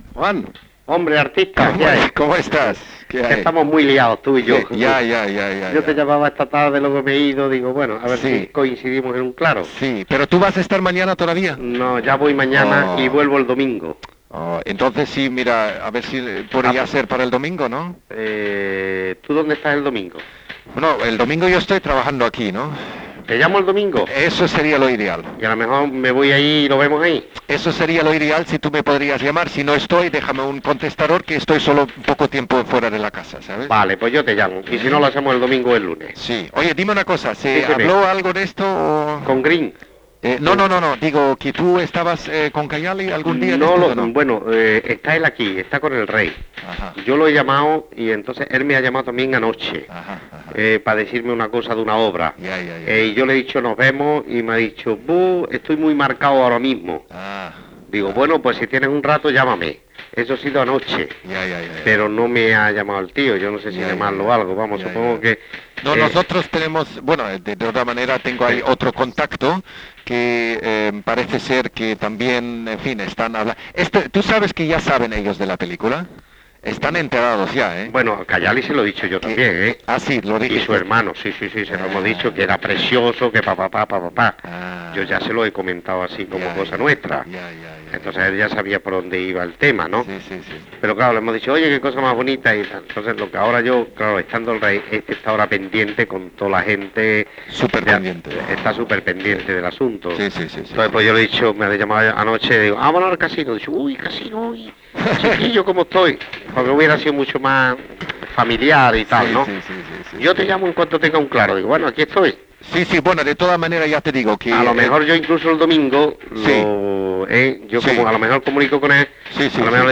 Conversación de 1982